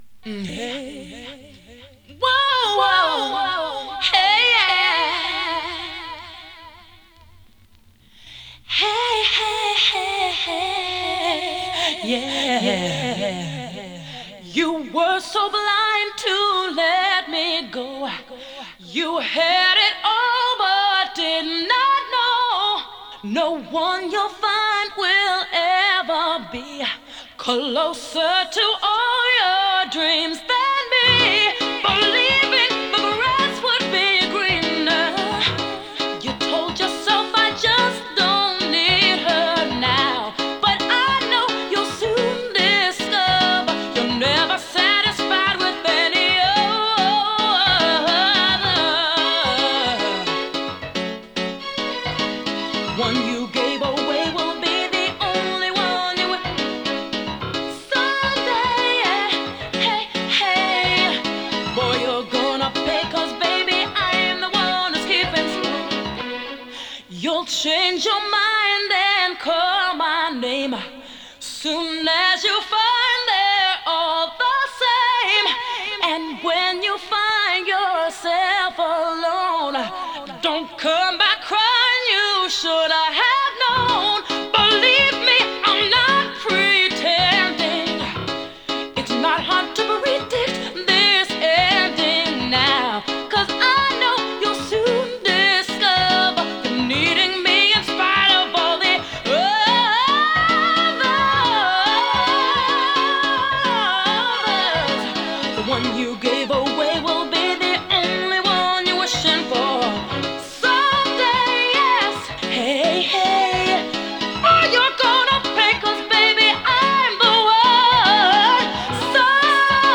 切なくも力強さを感じさせる90s RnB！